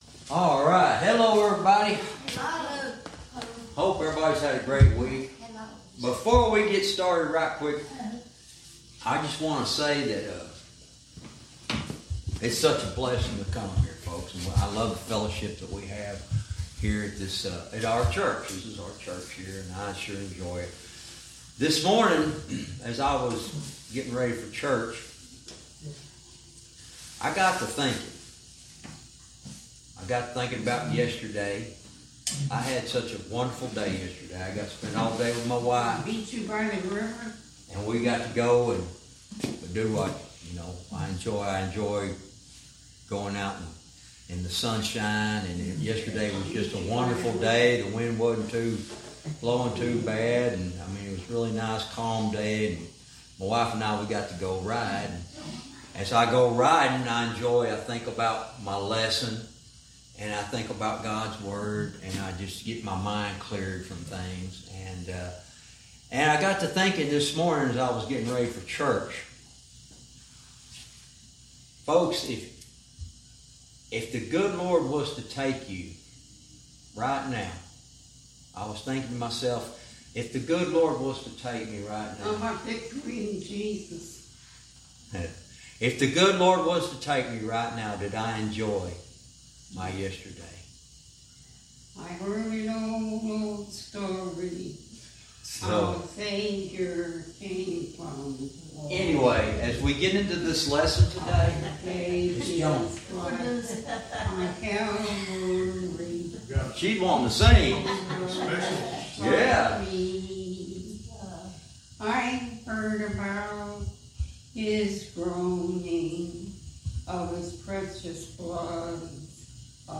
Verse by verse teaching - Jude lesson 87 verse 20